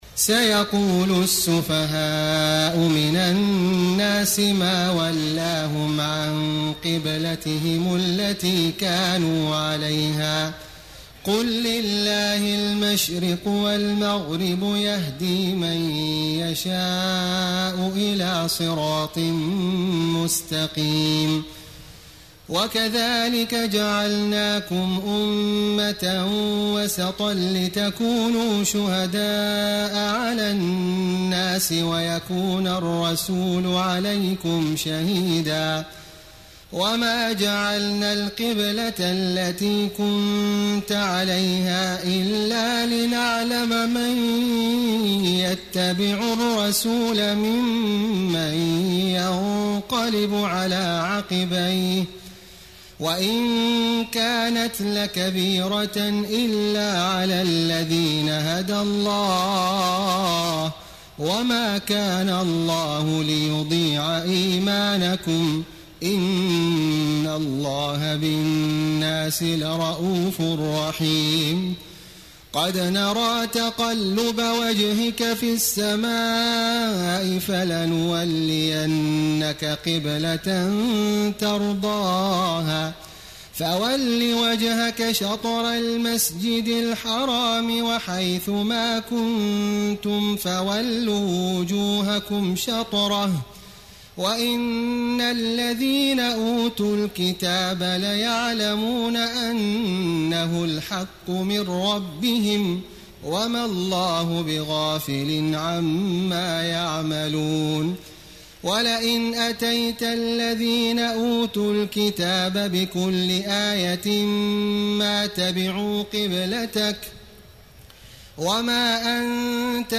تهجد ليلة 22 رمضان 1434هـ من سورة البقرة (142-218) Tahajjud 22 st night Ramadan 1434H from Surah Al-Baqara > تراويح الحرم المكي عام 1434 🕋 > التراويح - تلاوات الحرمين